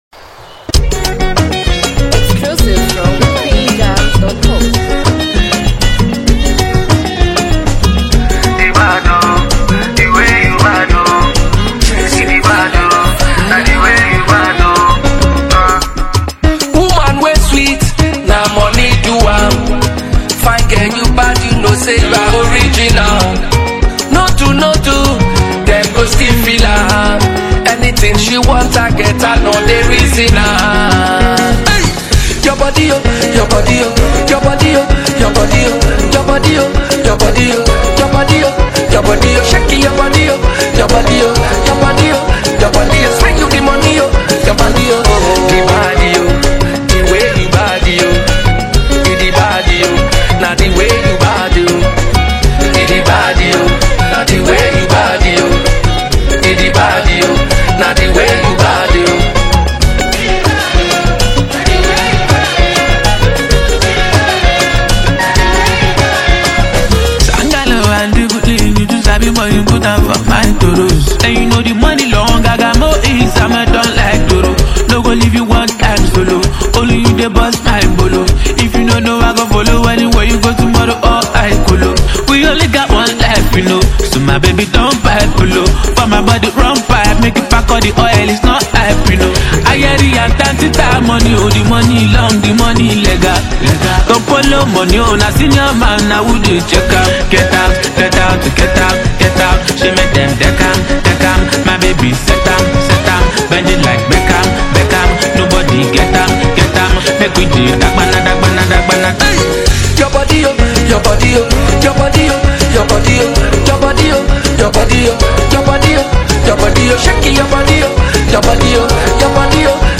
energetic track
signature highlife-infused style
gritty street-hop delivery
smooth, melodic vocals
The upbeat tempo and catchy hooks make it easy to replay.